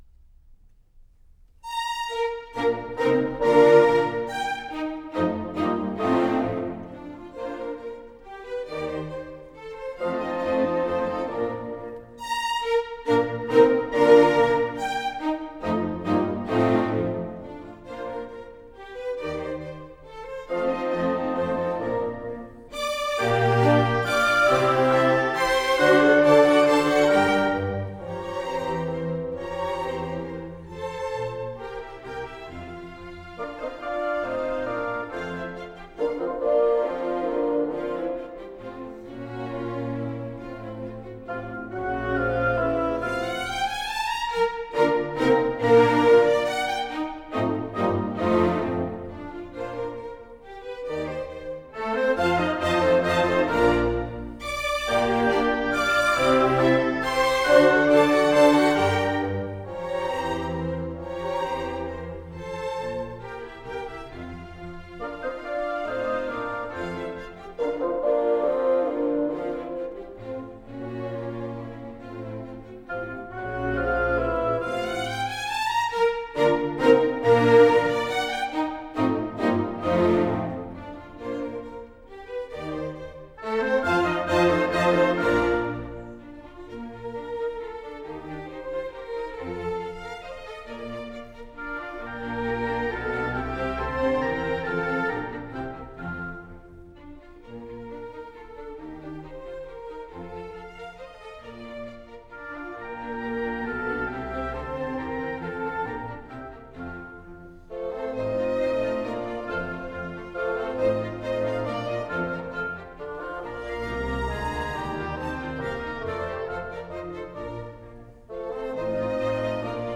» nhac-khong-loi